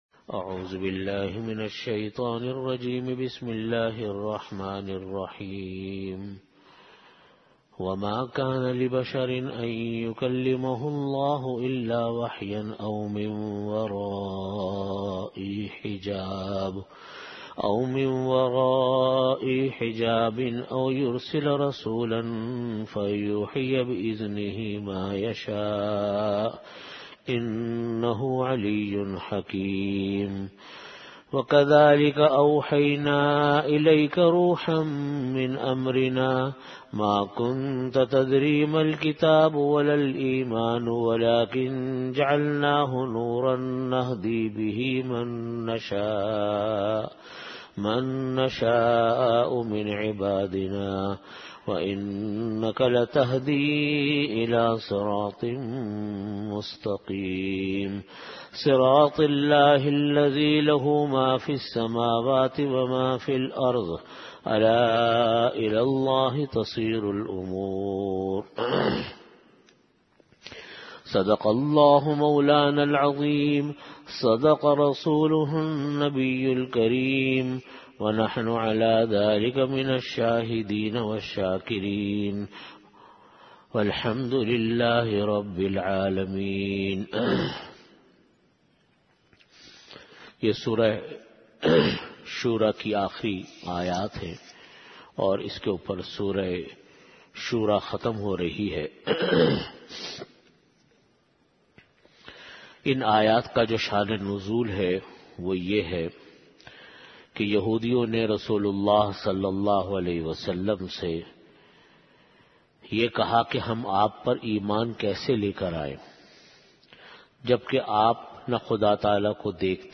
Delivered at Jamia Masjid Bait-ul-Mukkaram, Karachi.
Tafseer · Jamia Masjid Bait-ul-Mukkaram, Karachi